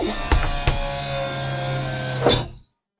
Hydraulic Press
A powerful hydraulic press cycling with building pressure, crushing impact, and release hiss
hydraulic-press.mp3